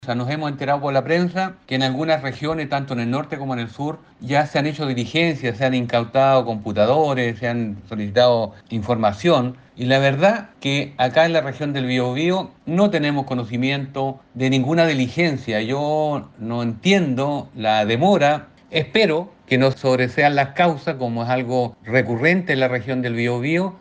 Como querellante en la arista Polizzi del caso Fundación “En Ti”, el diputado cercano a RN, Leonidas Romero, subrayó en la transparencia de la Fiscalía en otras regiones, esperando -dijo- que estas indagatorias no sigan el camino de otras de relevancia y que fueron sobreseídas.